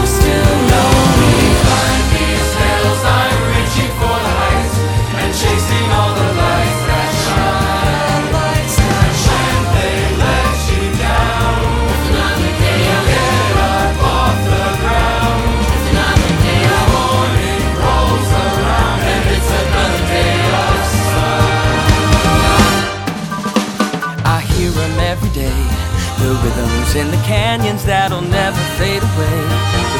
0 => "Musique de film"